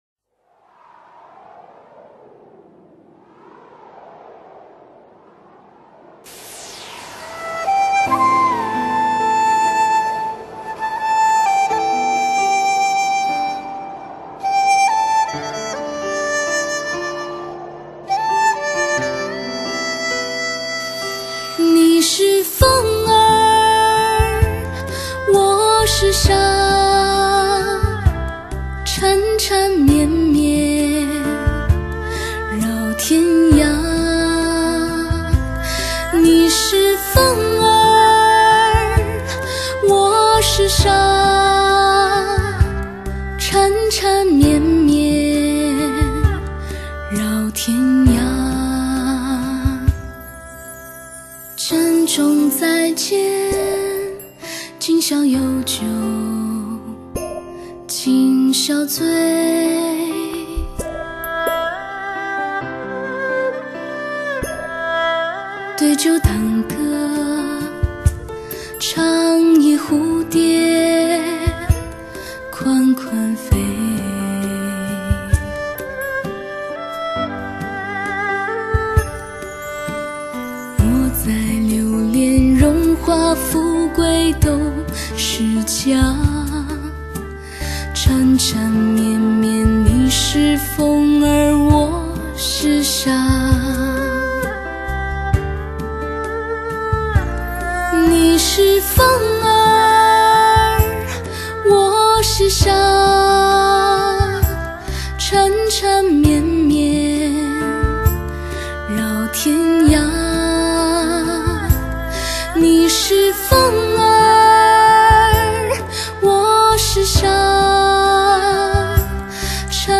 天籁发烧女声